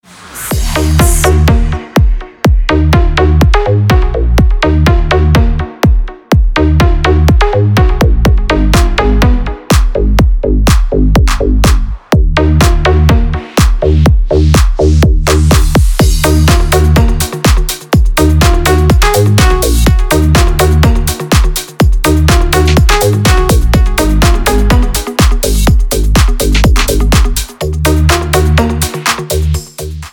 Рингтоны dance мотивов